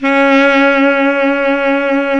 SAX B.SAX 0B.wav